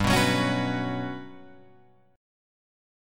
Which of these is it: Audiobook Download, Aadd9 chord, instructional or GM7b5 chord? GM7b5 chord